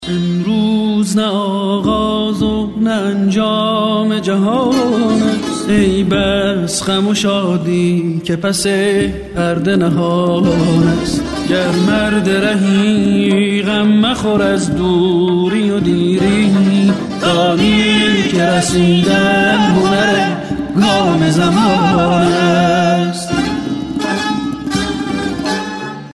آهنگ موبایل ملایم با کلام